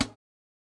HI HAT RAW2.wav